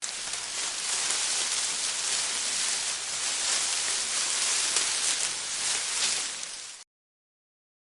Leaf Rustling on Branch
Nature
Leaf Rustling on Branch is a free nature sound effect available for download in MP3 format.
yt_hHQ2-QVKolk_leaf_rustling_on_branch.mp3